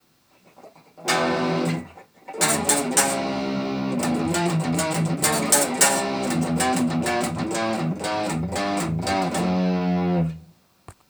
Here are also some sound examples played on a Black Star HT5 Metal combo:
Bridge Clean, Overdrive, Overdrive Muted:
These examples are recorded with my Windows phone.
So please excuse the poor technical quality.
pt3_bridge_overdrive_muted.m4a